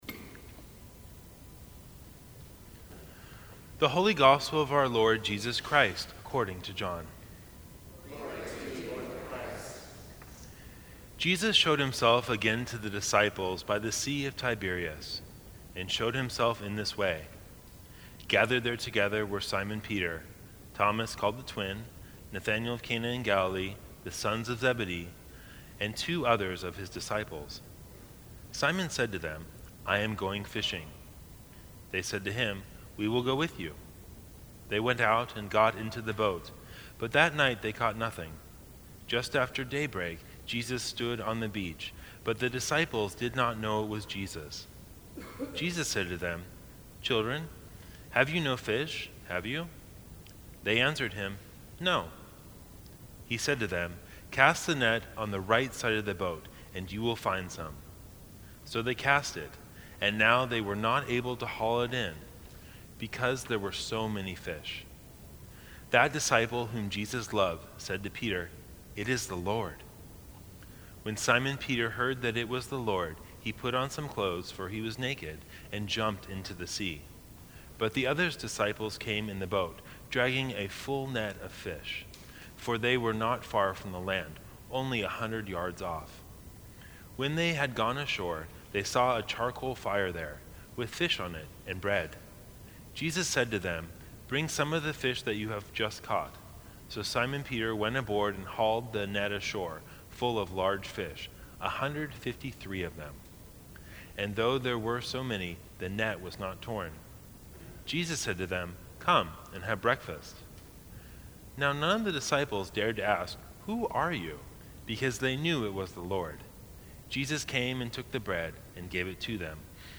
Sermons from St. Cross Episcopal Church Do You Love Me?